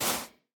Minecraft Version Minecraft Version 1.21.5 Latest Release | Latest Snapshot 1.21.5 / assets / minecraft / sounds / block / soul_sand / step3.ogg Compare With Compare With Latest Release | Latest Snapshot
step3.ogg